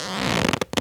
foley_leather_stretch_couch_chair_27.wav